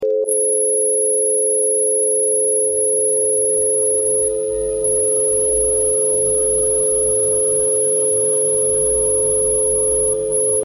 Frecuencia Rife para curar dolencias, sound effects free download